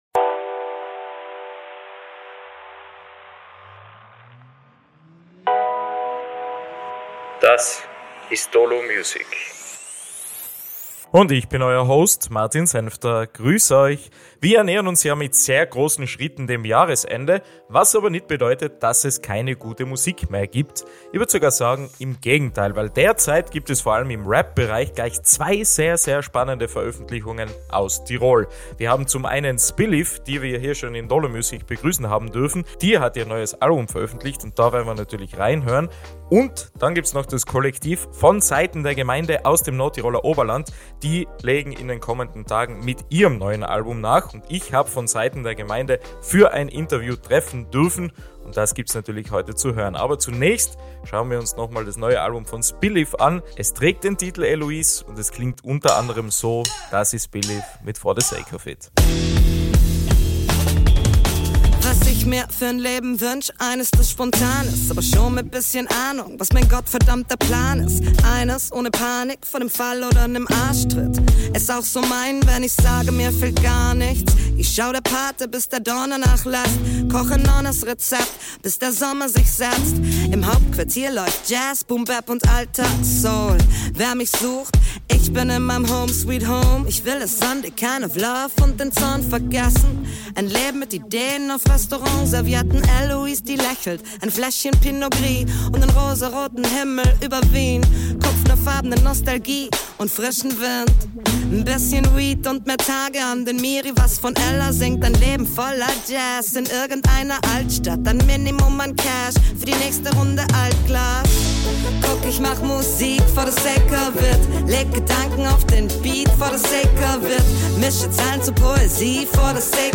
Diesen Song gibt’s natürlich auch in der neuen Folge von Dolo Music zu hören.